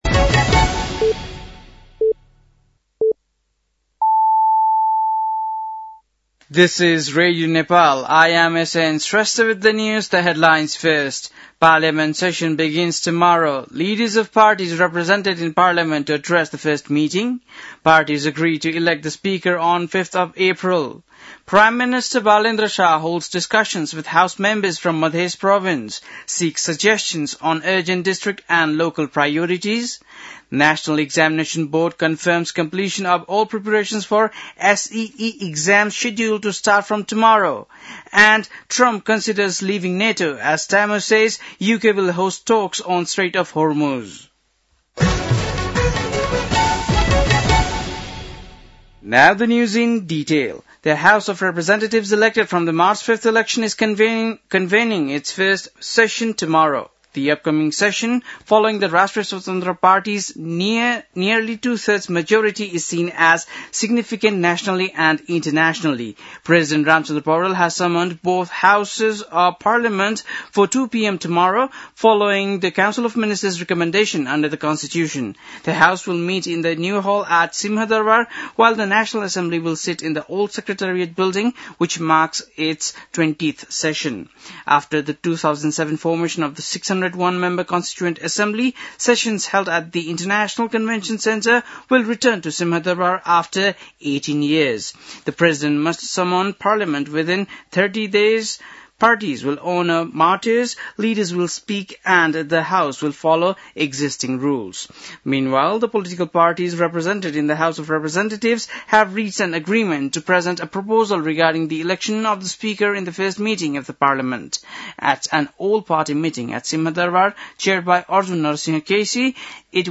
बेलुकी ८ बजेको अङ्ग्रेजी समाचार : १८ चैत , २०८२
8-pm-english-news-12-18.mp3